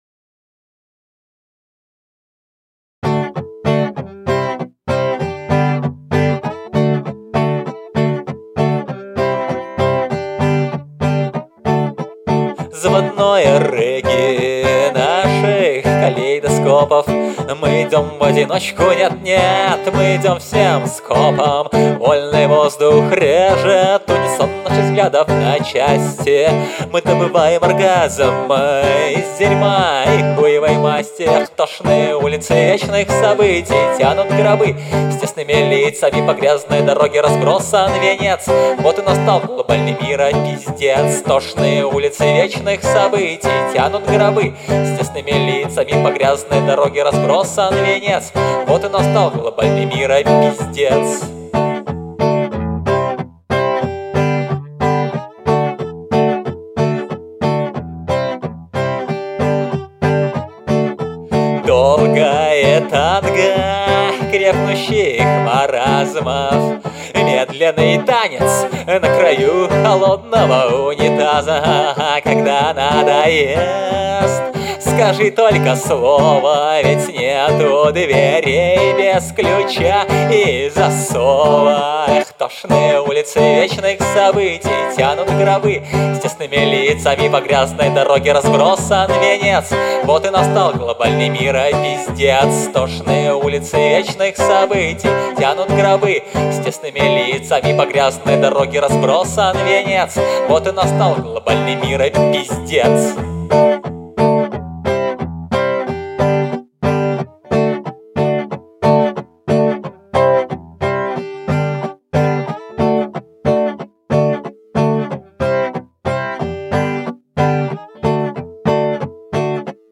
Музыкальный хостинг: /Альтернативная